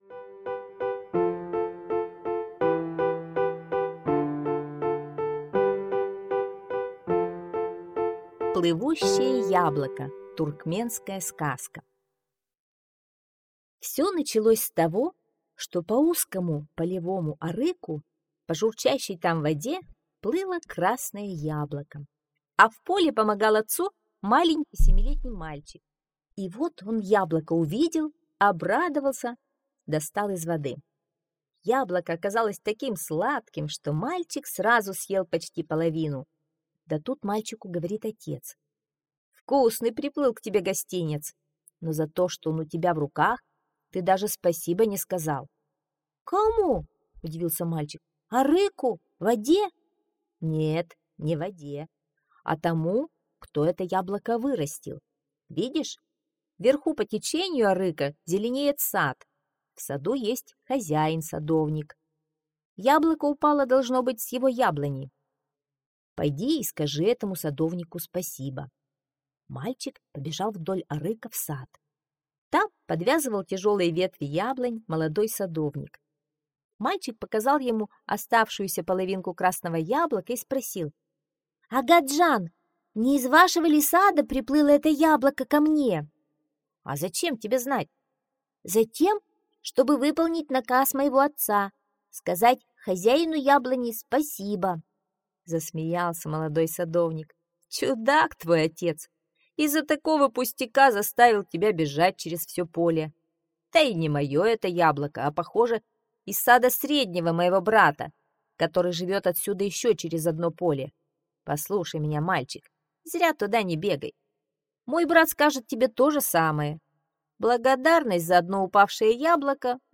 Яблоко, плывущее по воде – туркменская аудиосказка - слушать онлайн